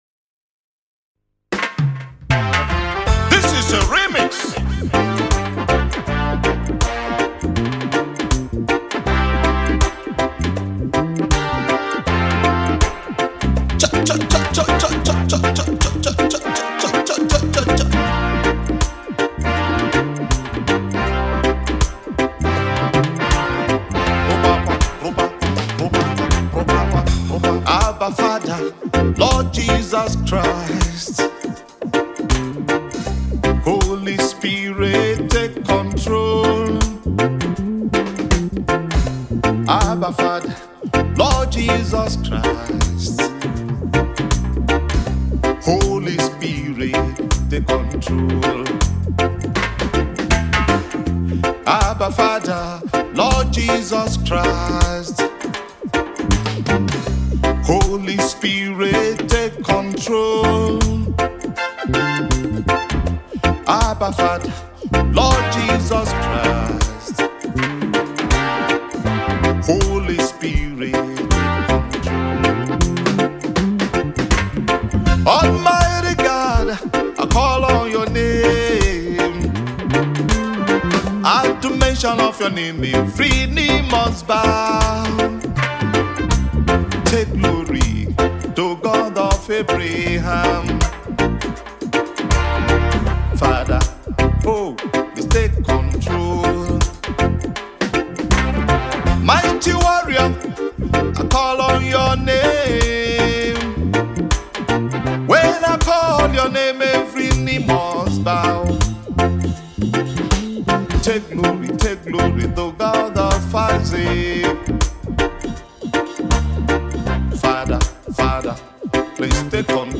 Renowned Nigerian gospel music minister
With a unique blend of soulful vocals and heartfelt lyrics
With poignant lyrics and an anointed melody